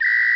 Whistle Lo Sound Effect
whistle-lo.mp3